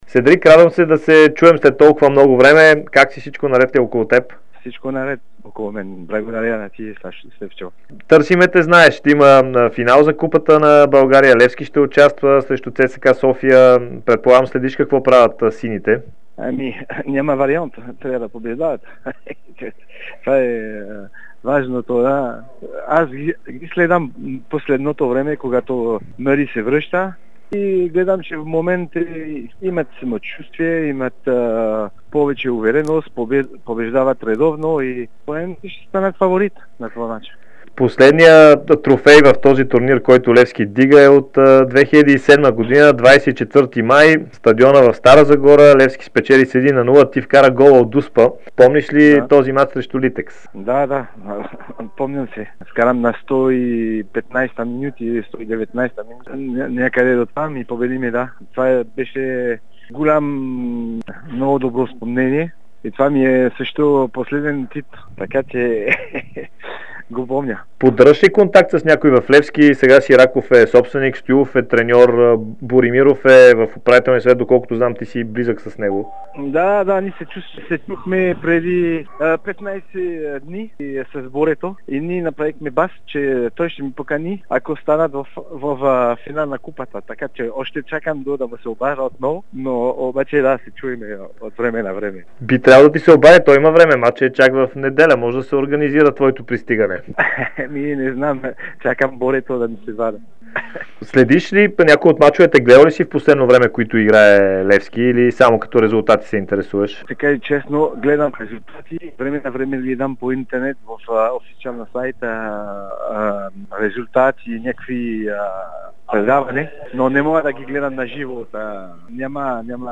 Бившият футболист на Левски Седрик Бардон даде специално интервю за Дарик и dsport в дните преди финала за Купата на България.